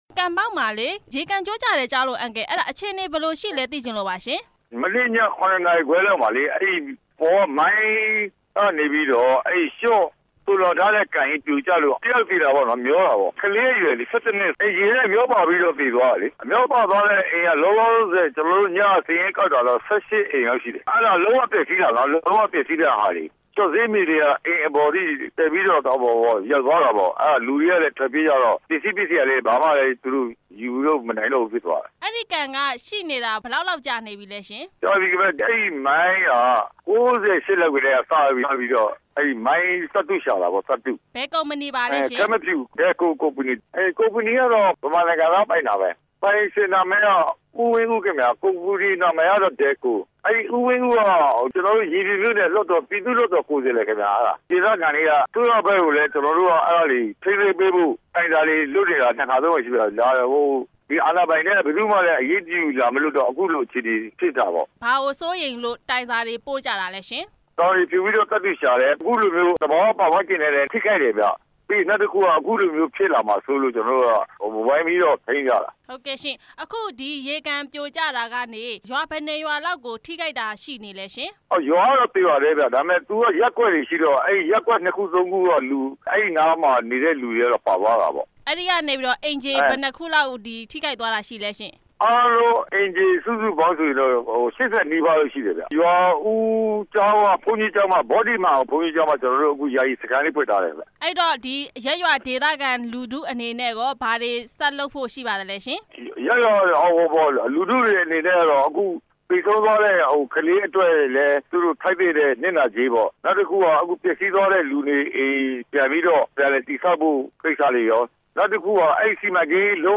Delco ကုမ္ပဏီရဲ့ စွန့်ပစ်ကန် ပြိုကျတဲ့အကြောင်း မေးမြန်းချက်